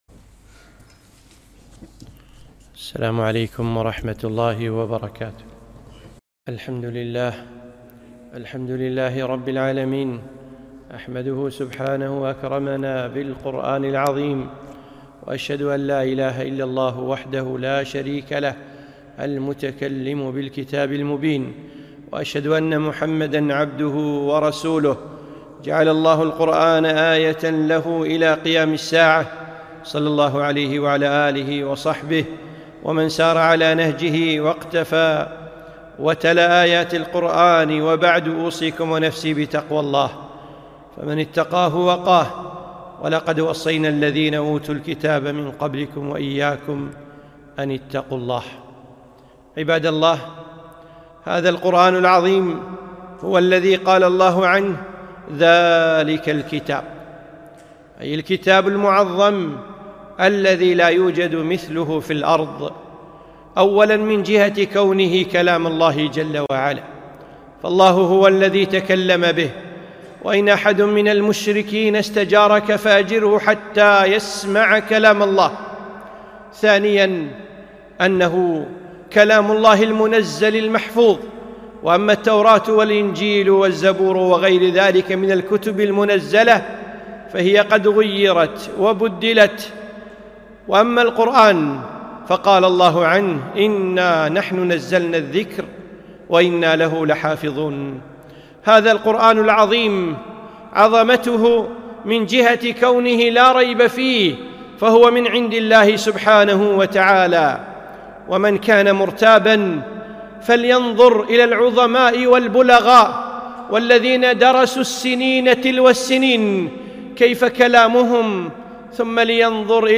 خطبة - القرآن العظيم